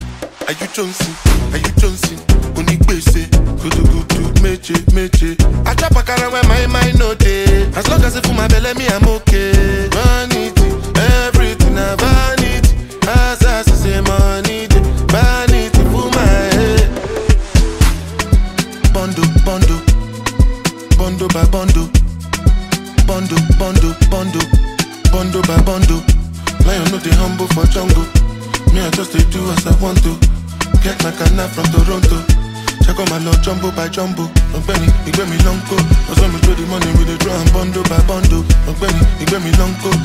Kategori Rap